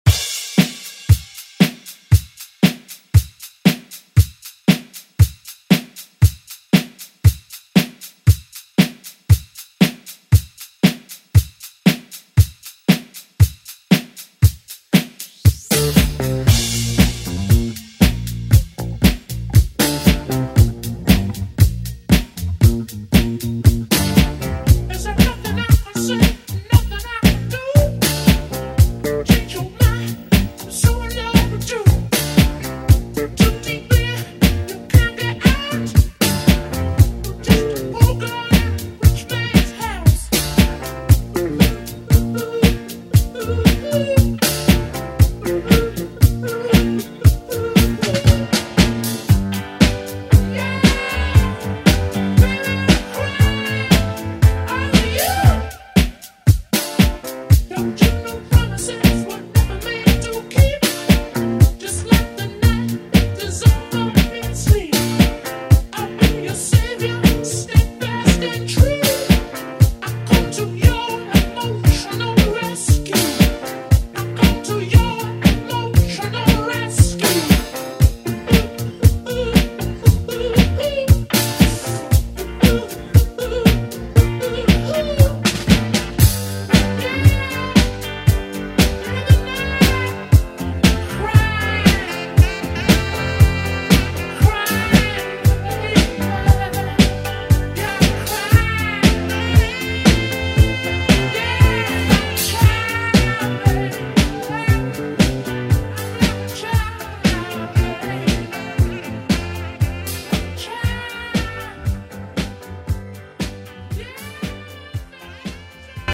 Throwback Pop Rock Music Extended ReDrum Clean 95 bpm
Genre: RE-DRUM